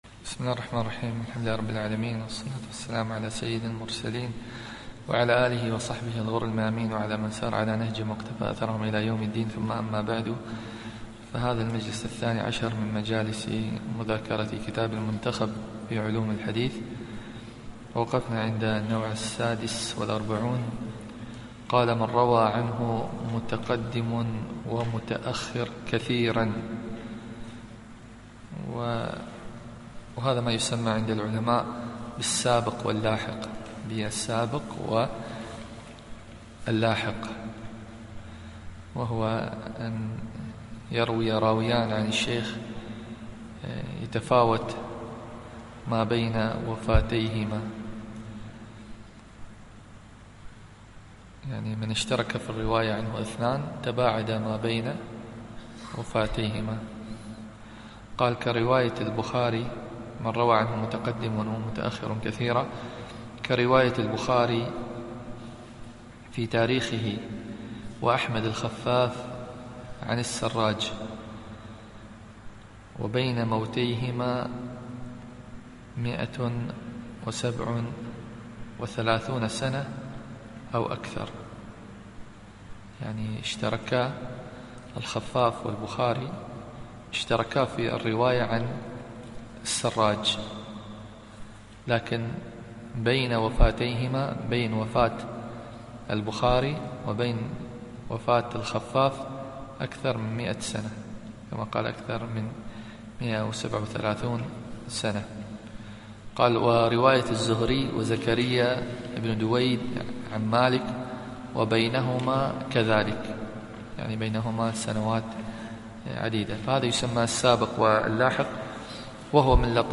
الدرس الثاني عشر والأخير